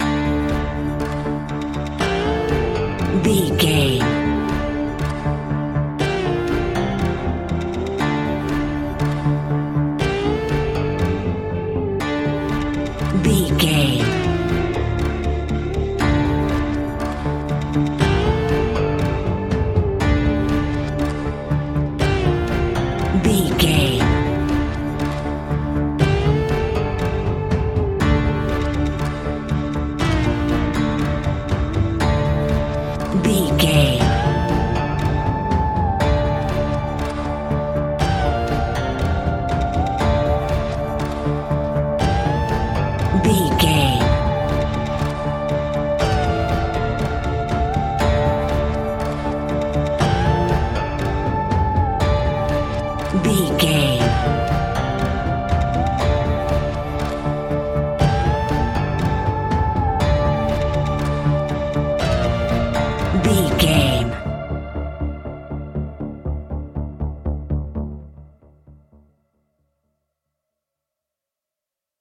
Aeolian/Minor
D
ominous
dark
haunting
eerie
electric guitar
drums
synthesiser
horror music